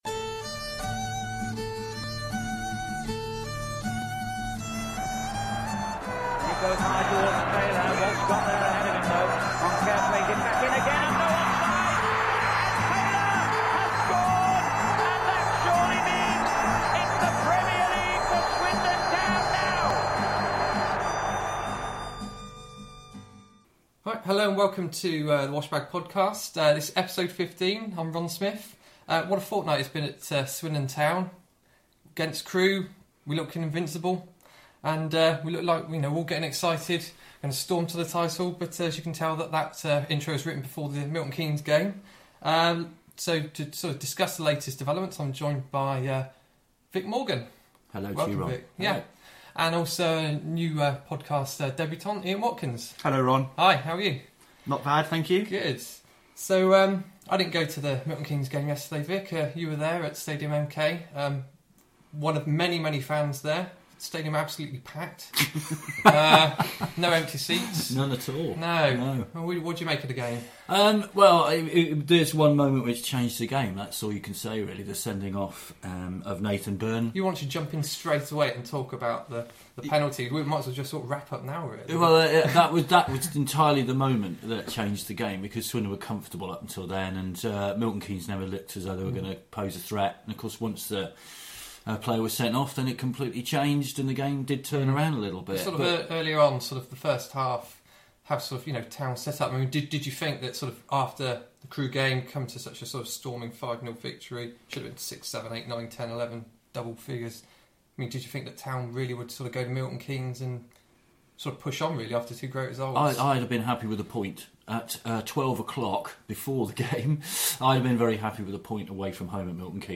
The three discuss Town’s 1-1 draw at MK Dons; whether Nathan Byrne deserved his red card; are backpasses to Wes Foderingham a necessary part of Town’s tactics; we discuss your responses to our question ‘are the 2013/14 crop at Swindon in a stronger position to push for promotion’; the strength of League One following the transfer window; and a quick look-ahead with predictions for next Saturday’s trip to Wolves…